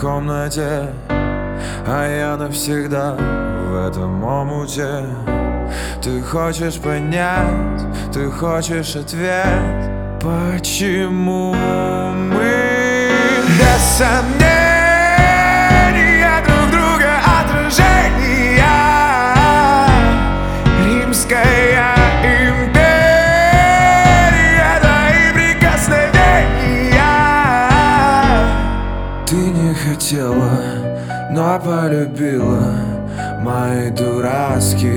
Pop Rock Pop